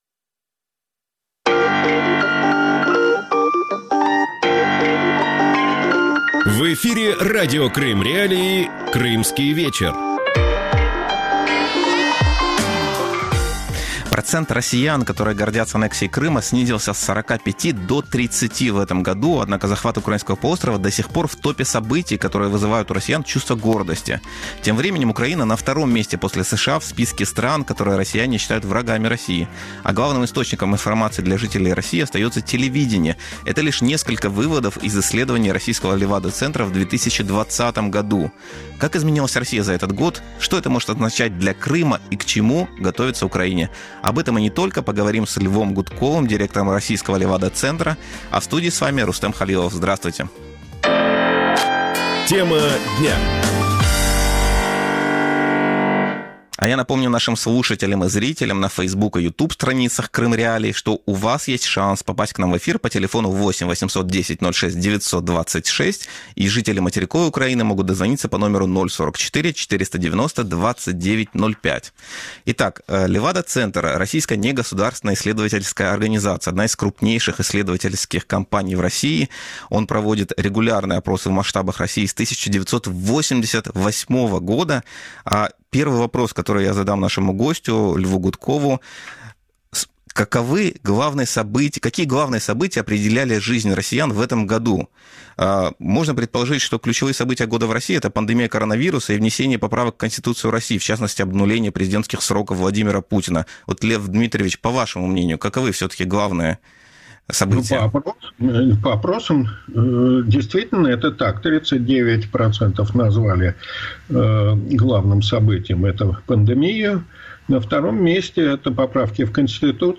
Его собеседник: директор российского «Левада-центра» Лев Гудков.